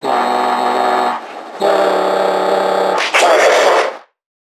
NPC_Creatures_Vocalisations_Infected [78].wav